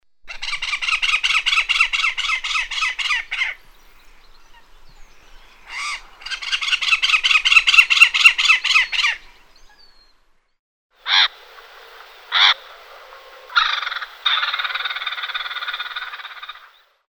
Cucut reial
Clamator glandarius
Clamator-glandarius.mp3